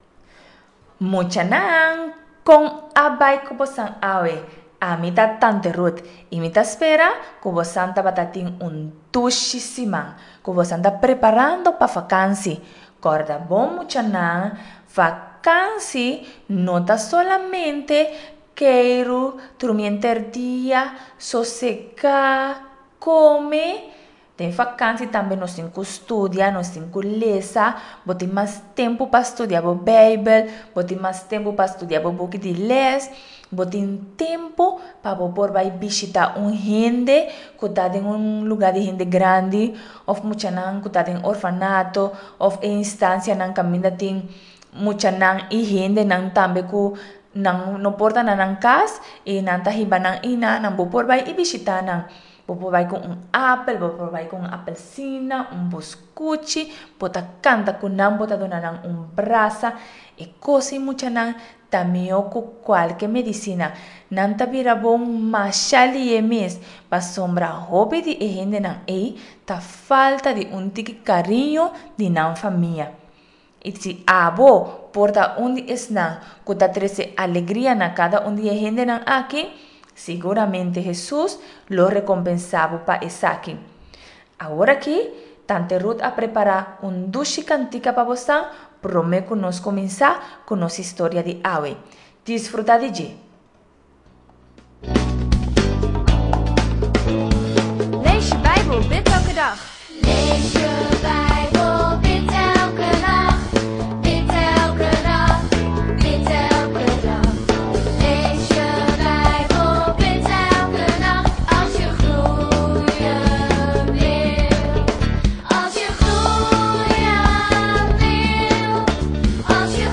Nos ta adora Dios ora ku nos ta kanta, kantika nan gososo di alabansa.